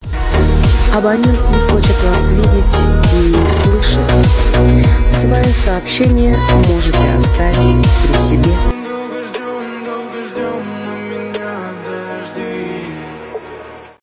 Категория: Русские рингтоныКлубные рингтоны
Тэги: ремиксыженский голосtik-tokна бывшегона бывшую